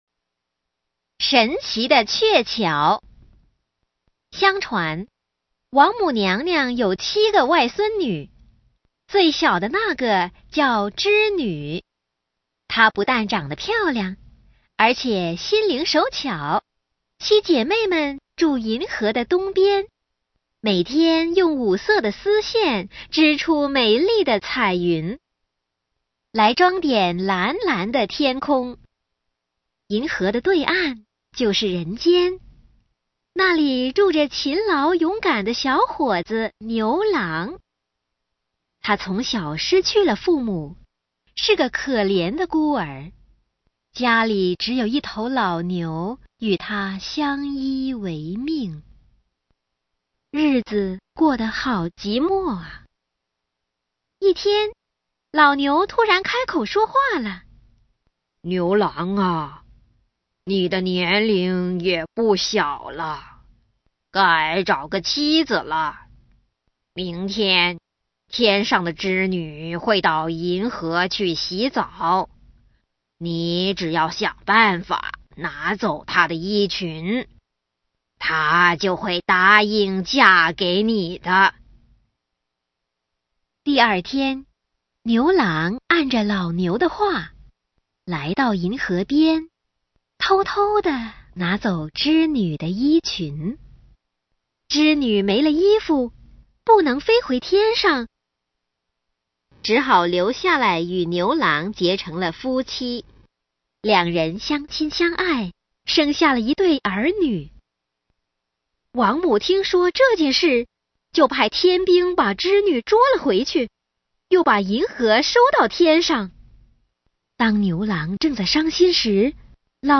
(звук в тайваньском варианте мне всетаки больше нравиться, да и меньше в 5 раз)
Всё это я "выковыриваю" из CD-ROMов к учебникам по гоюй для младших классов тайваньской школы.